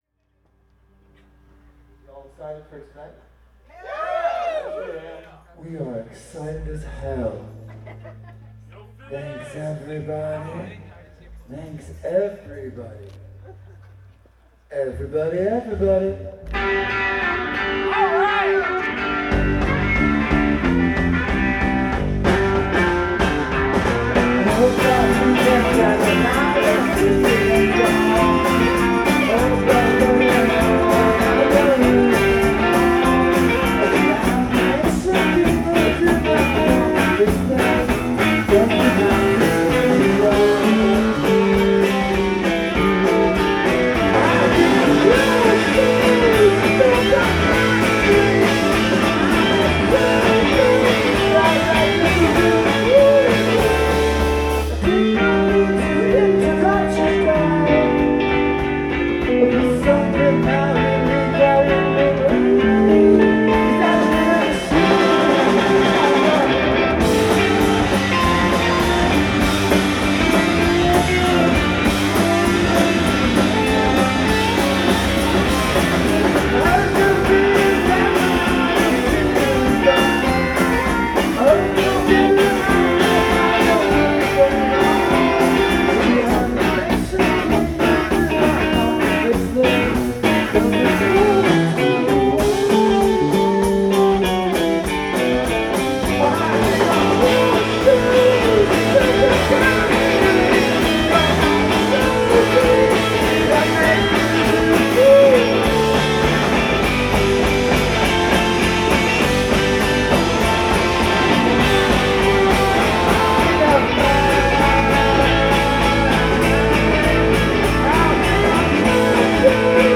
Live at All Tomorrow’s Parties NYC 2008
in Monticello, NY